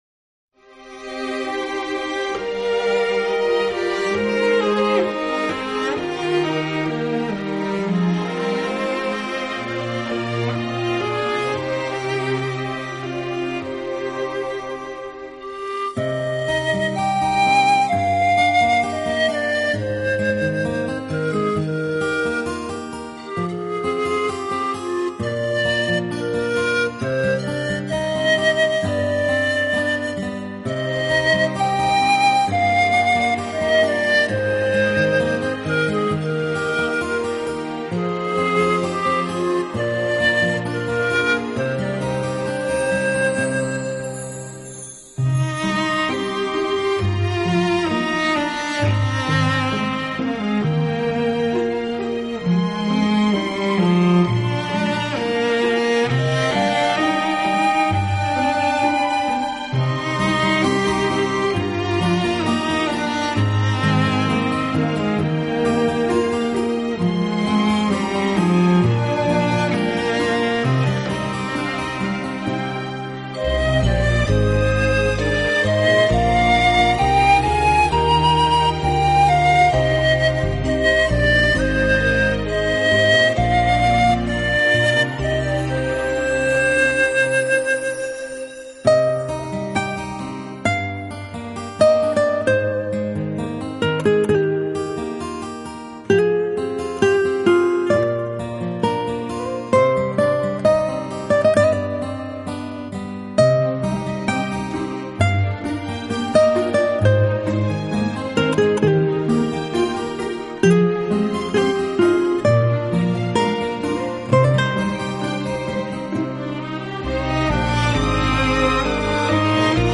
陶笛
音乐类型：New Age / Instrumental